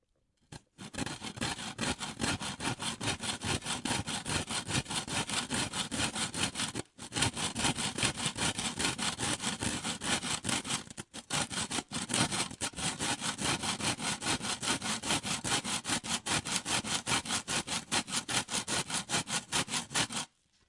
描述：řezánílipoudo polystyrenu
Tag: 聚苯乙烯